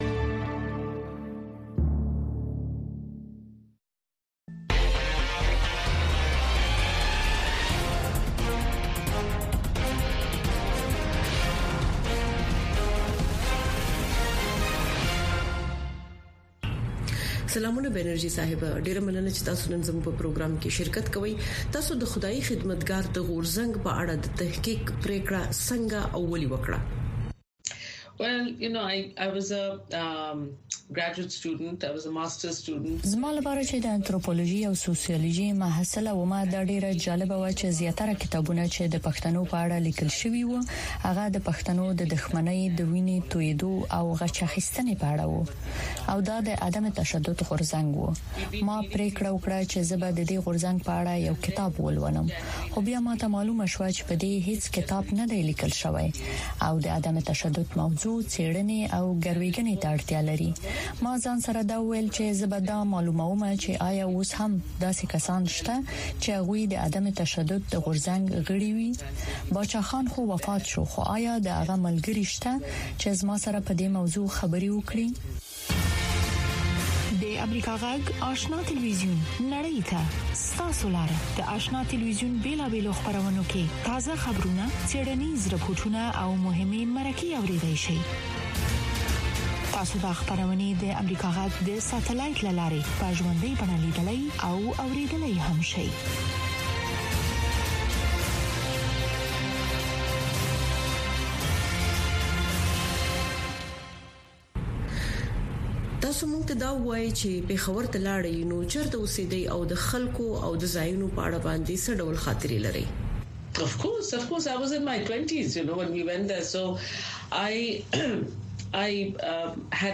ځانګړې مرکه
د افغانستان او نړۍ د تودو موضوعګانو په هکله د مسولینو، مقاماتو، کارپوهانو او څیړونکو سره ځانګړې مرکې هره چهارشنبه د ماښام ۶:۰۰ بجو څخه تر ۶:۳۰ بجو دقیقو پورې د امریکاغږ په سپوږمکۍ او ډیجیټلي خپرونو کې وګورئ او واورئ.